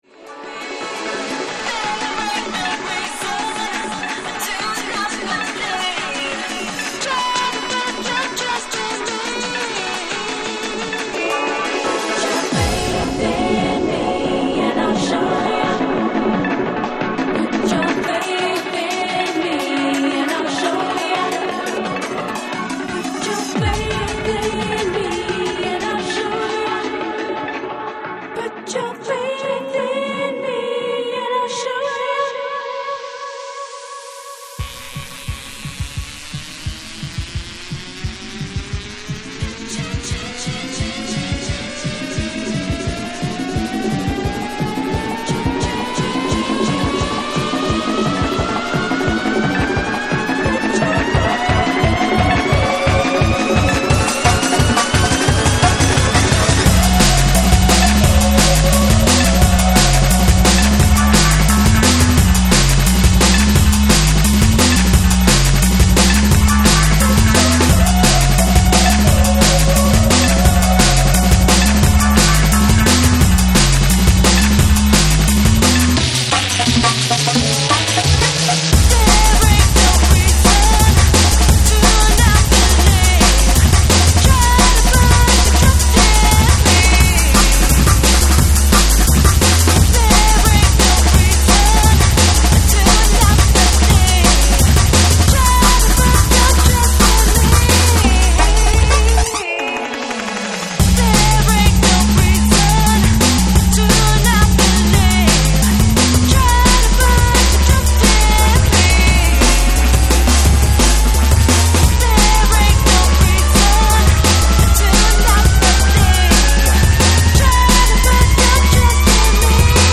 and hi-energy hyperdisco/liquid funk D&B